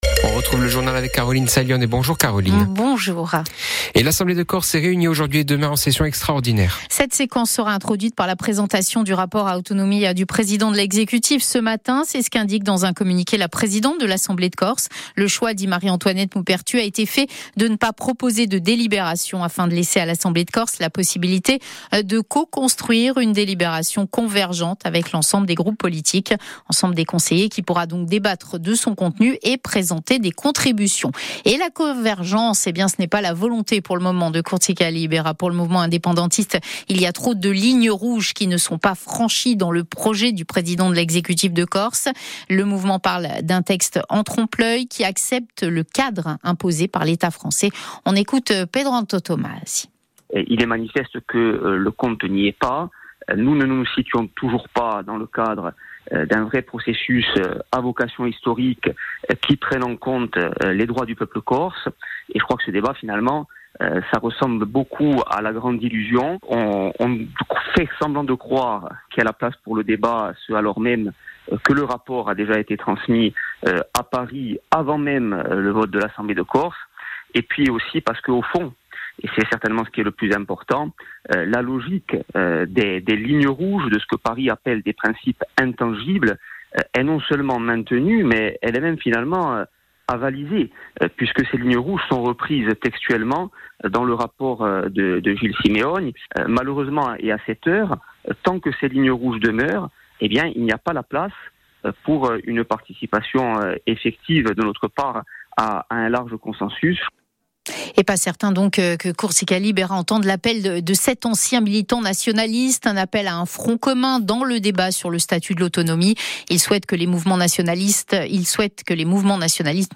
Conférence de presse de la section